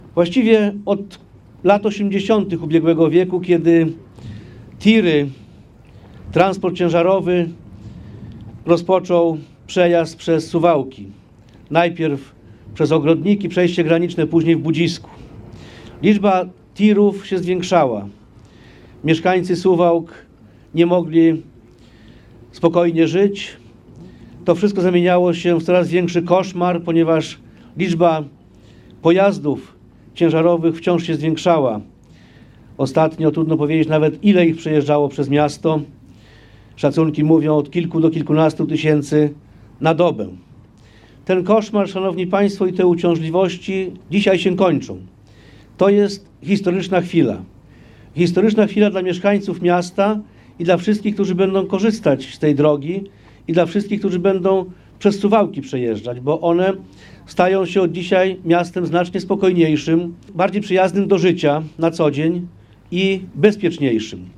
Jarosław Zieliński, poseł i sekretarz stanu w MSWiA mówił między innymi o latach zabiegów o budowę drogi i kolosalnej zmianie, jaką niesie ona  mieszkańcom.